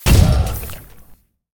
CosmicRageSounds / ogg / general / combat / battlesuit / move1.ogg